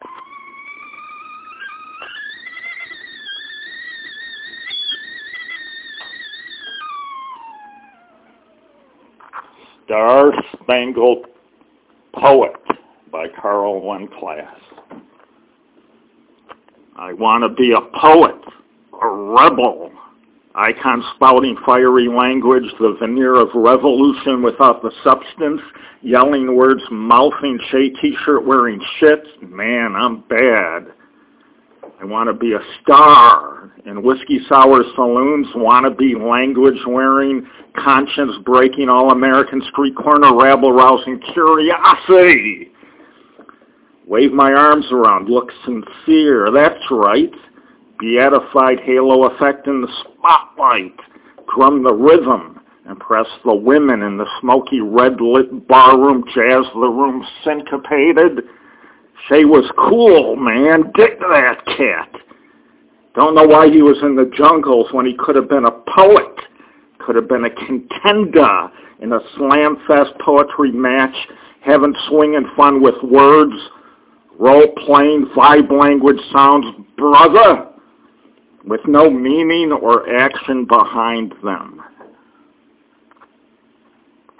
FYI: It’s a trifle sarcastic.
(NOTE:  The opening to this poem is supposed to be a rocket. A failed one obviously.)